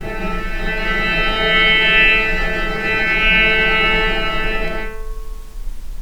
vc_sp-A3-pp.AIF